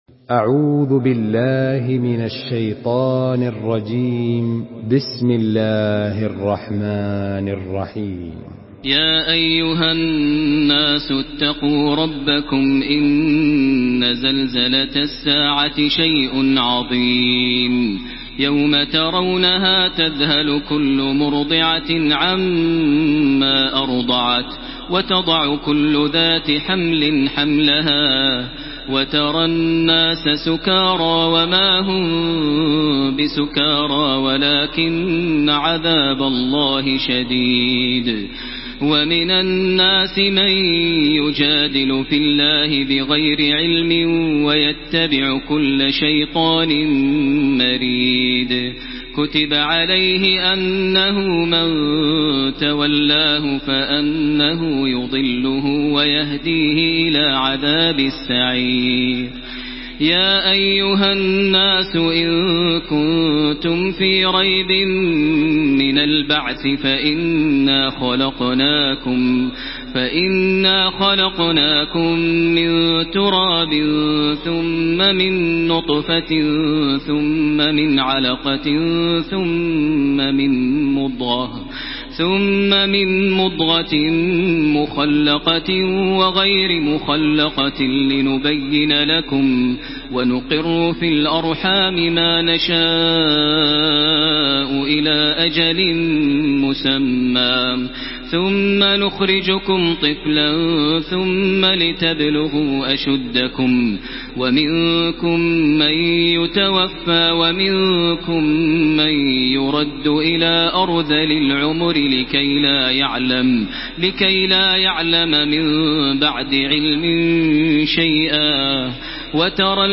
Surah Al-Haj MP3 by Makkah Taraweeh 1433 in Hafs An Asim narration.
Murattal